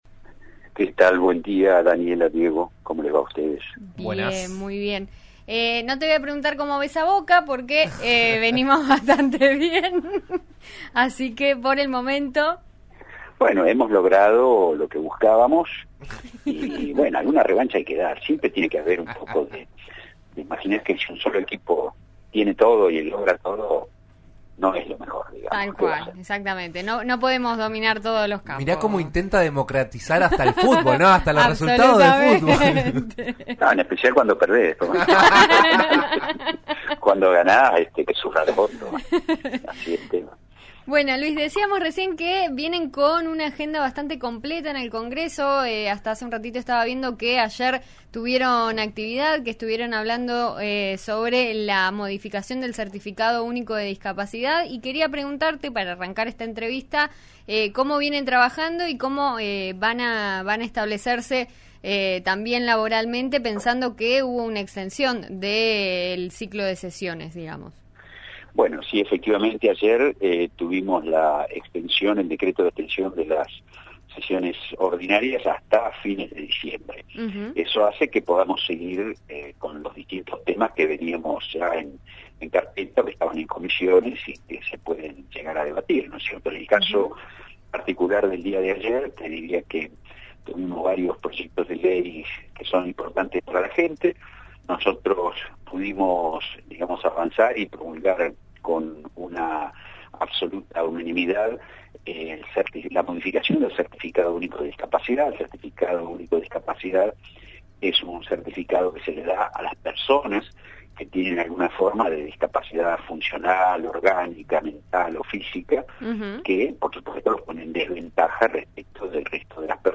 El diputado de Juntos Somos Río Negro habló en RN RADIO sobre el proyecto, que se encuentra aún en Comisión.